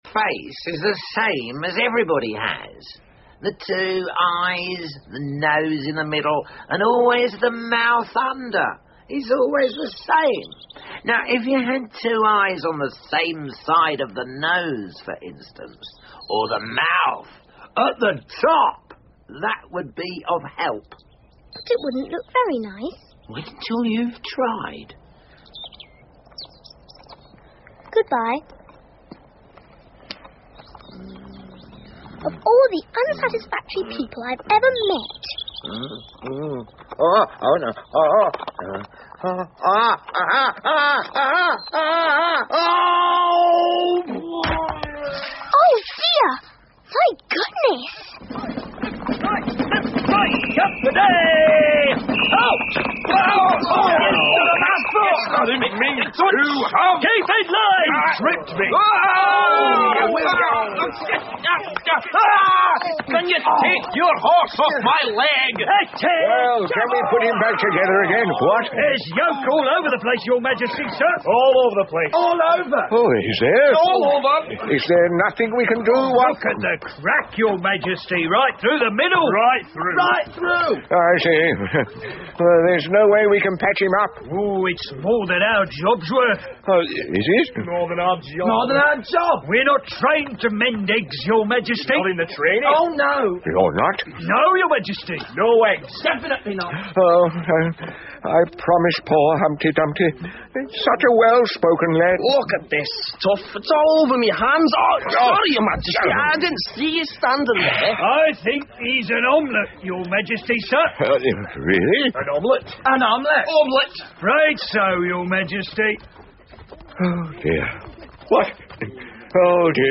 Through The Looking Glas 艾丽丝镜中奇遇记 儿童广播剧 15 听力文件下载—在线英语听力室